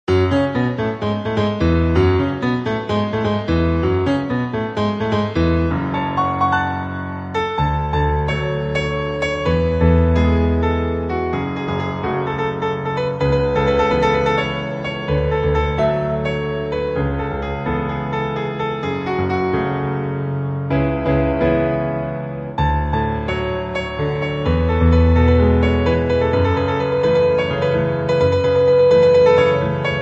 • Key: D Major
• Instruments: Piano solo
• Genre: TV/Film, Anime